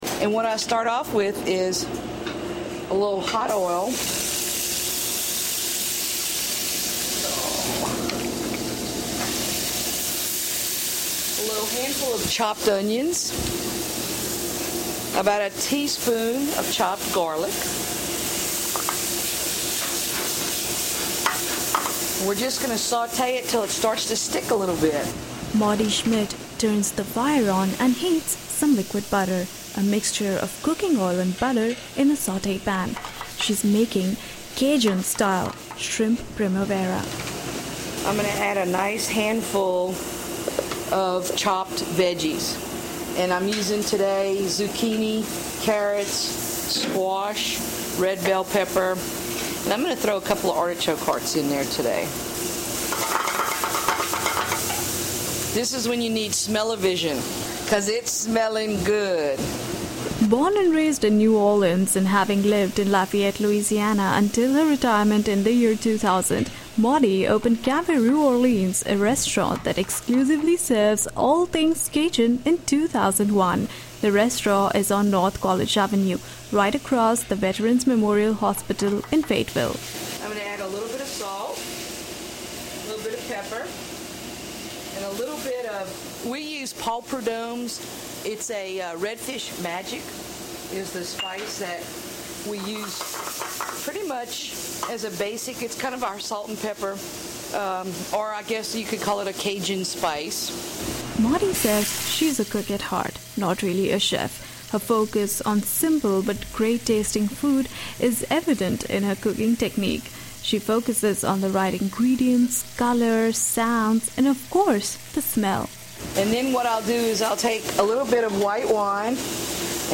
We spend time in the Cafe Rue Orleans kitchen as we prepare for Thursday's Chefs in the Garden at the Botanical Gardens of the Ozarks.